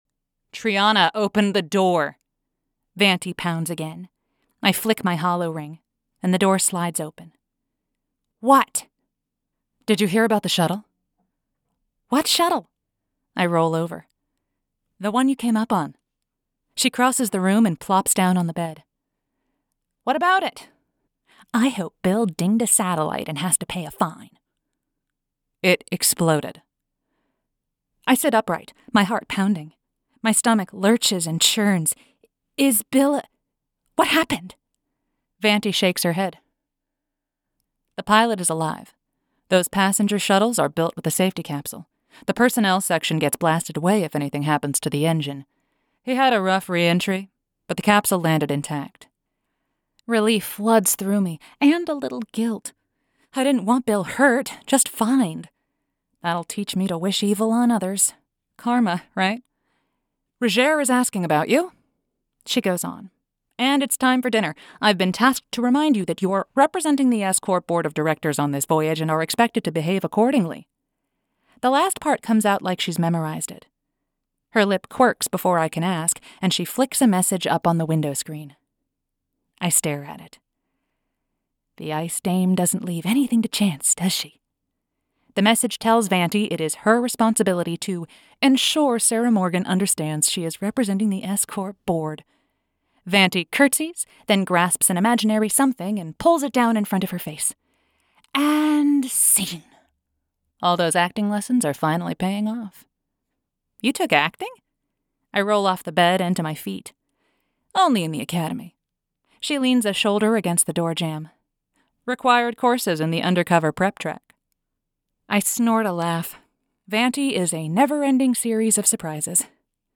The Rings of Grissom Audiobook